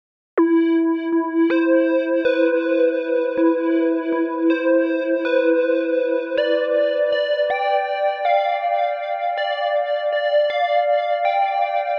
Tag: 80 bpm Pop Loops Synth Loops 2.02 MB wav Key : A